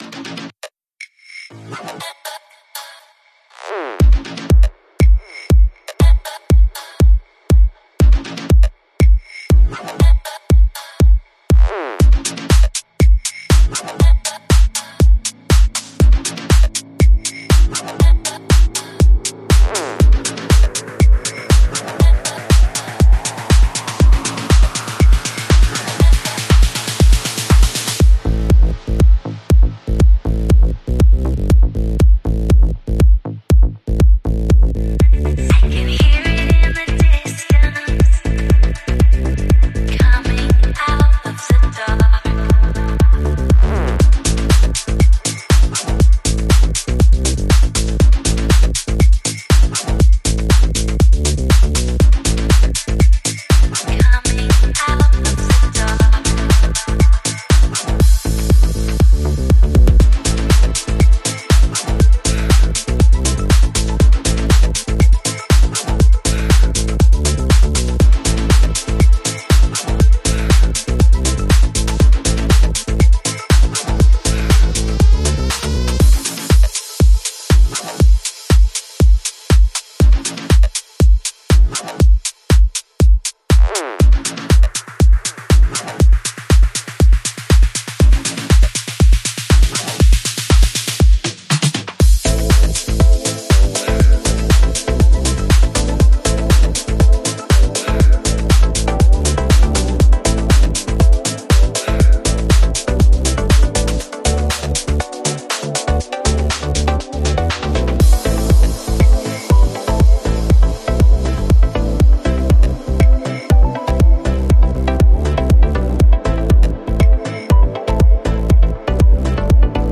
Genre: Melodic House